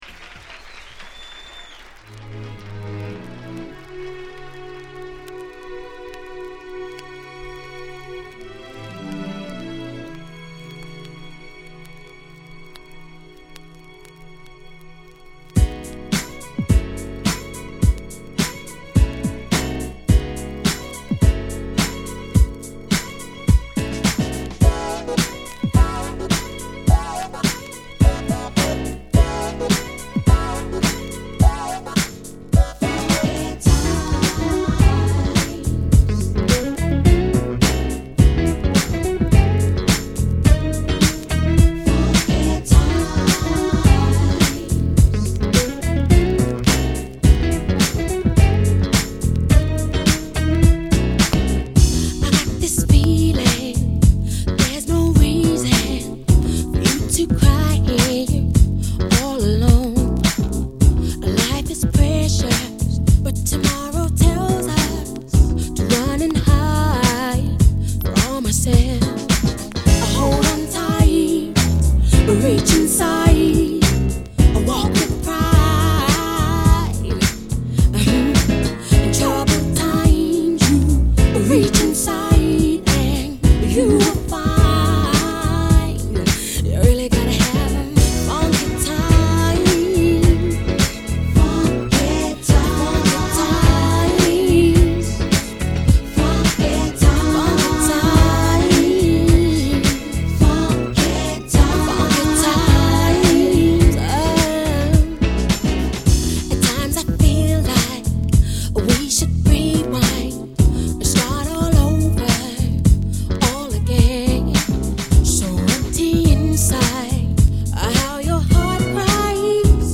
UK-garage